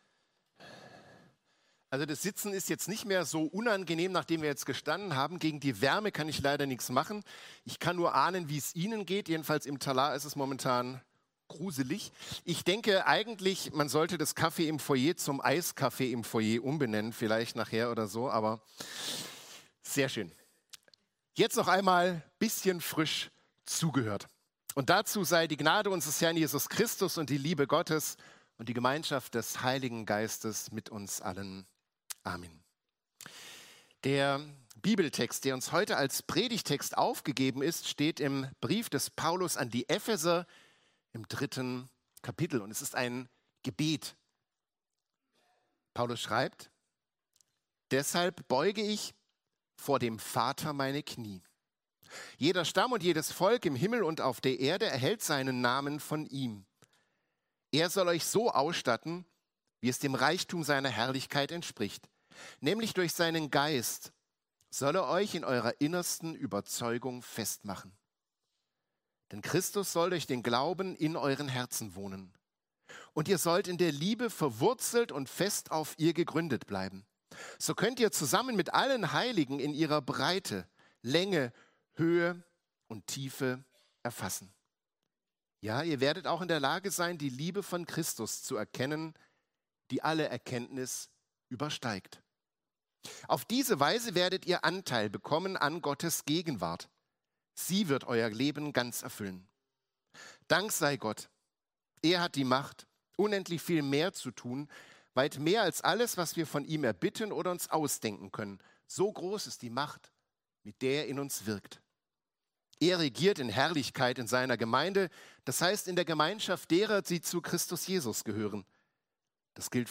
Die Liebe Christi erkennen – Exaudi